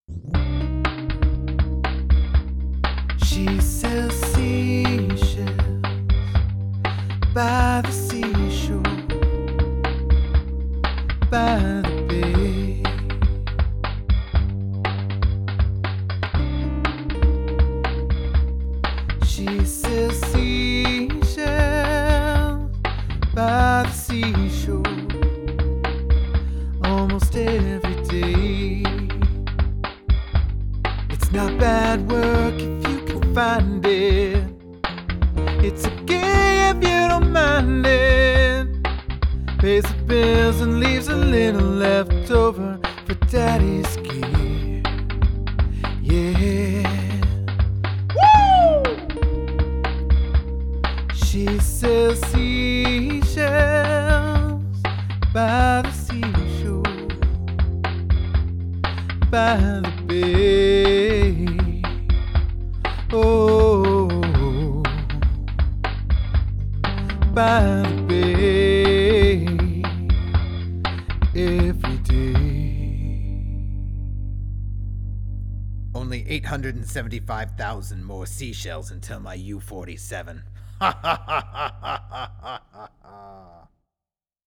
Here are the first batch of clips with the vocal imbedded into the track :